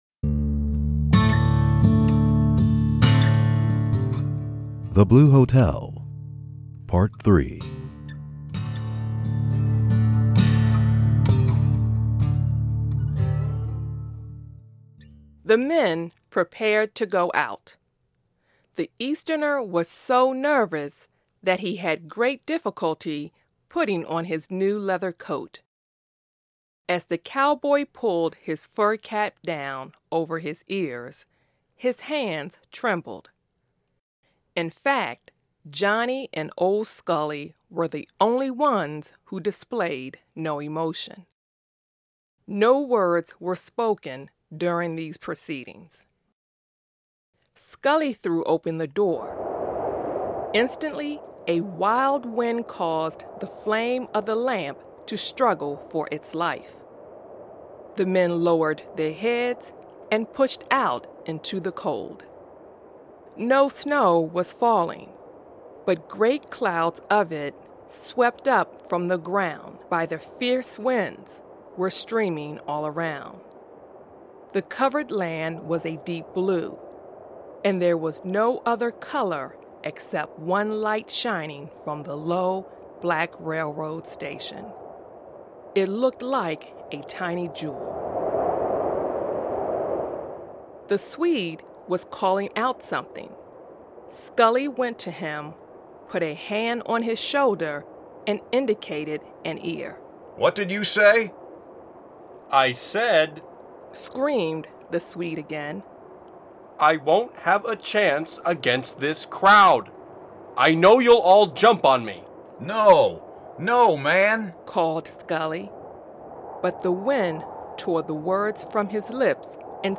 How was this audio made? The audio was recorded and produce by VOA Learning English.